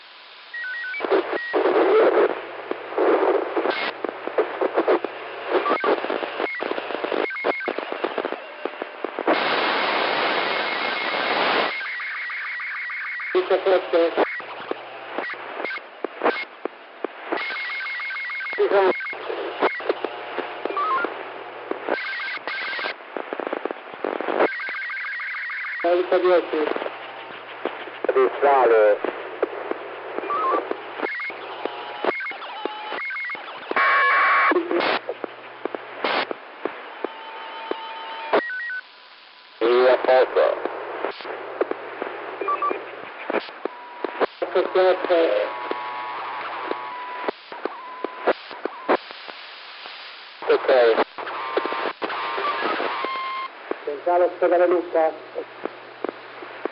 помехи arissat1